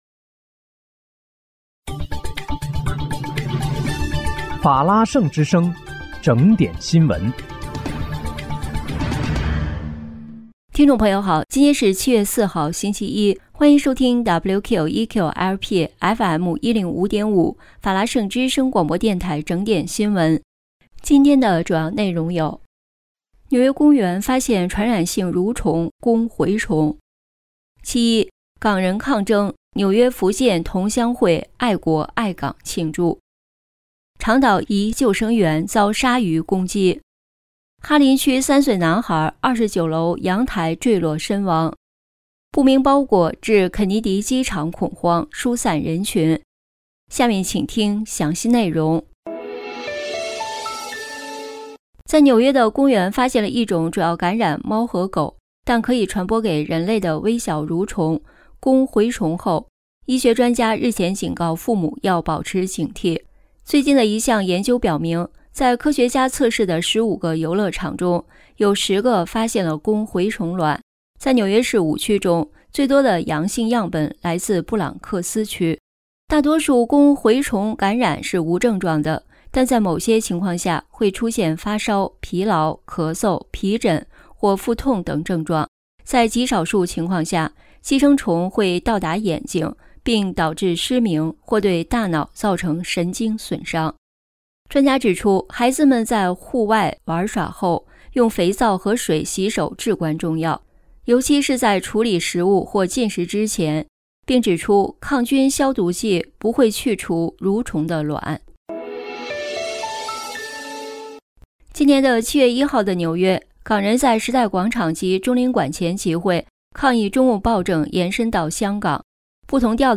7月4日（星期一）纽约整点新闻